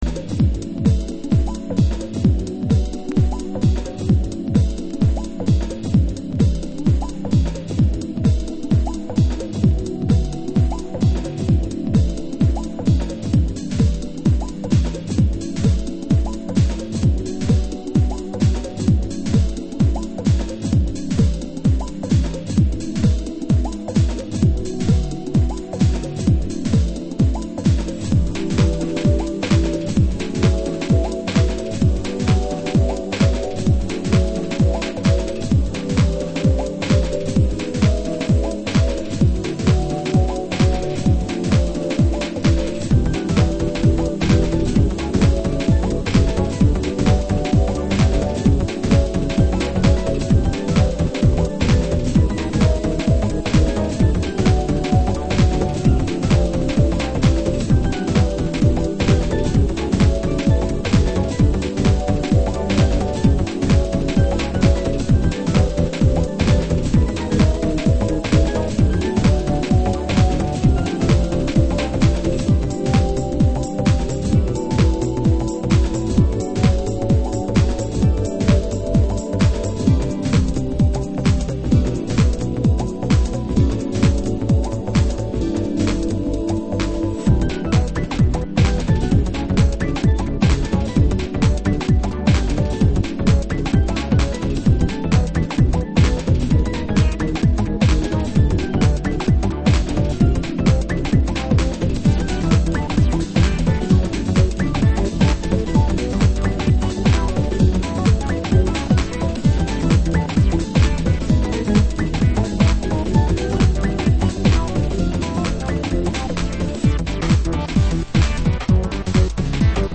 Early House / 90's Techno
ピュアな鳴りとメロデーに痺れます。